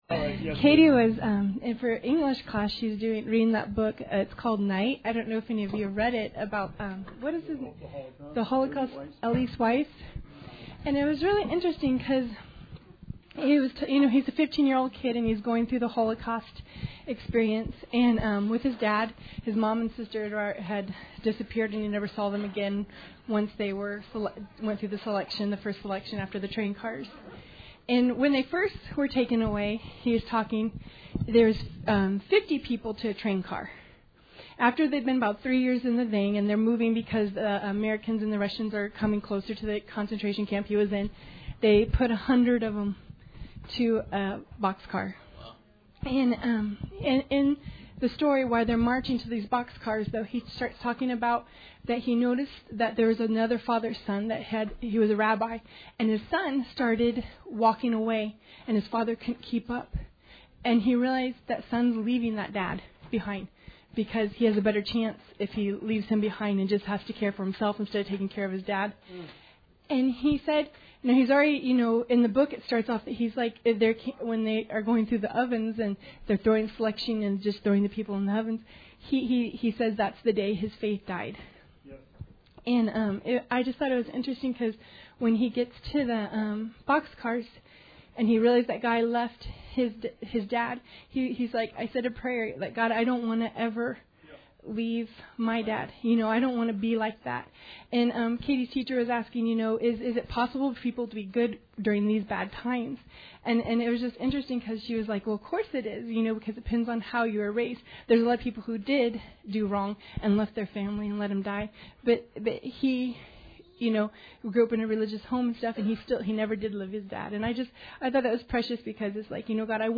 Bible Study 5/2/18